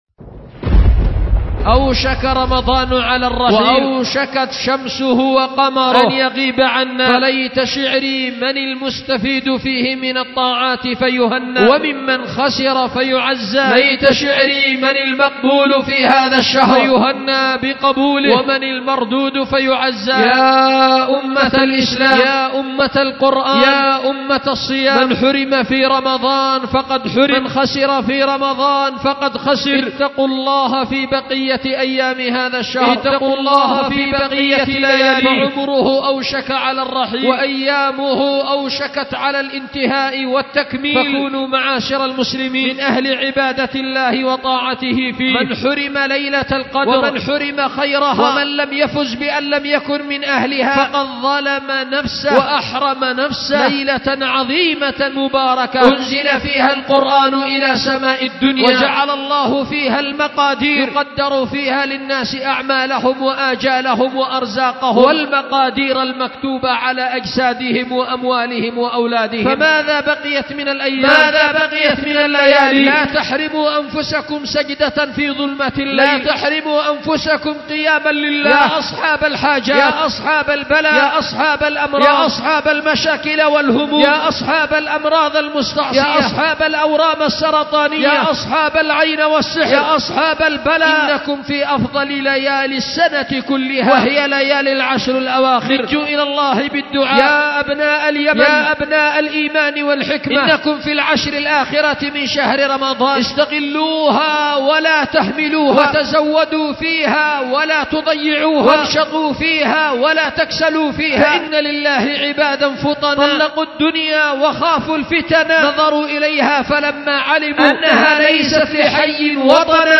خطبة
أُلقيت بدار الحديث للعلوم الشرعية بمسجد ذي النورين ـ اليمن ـ ذمار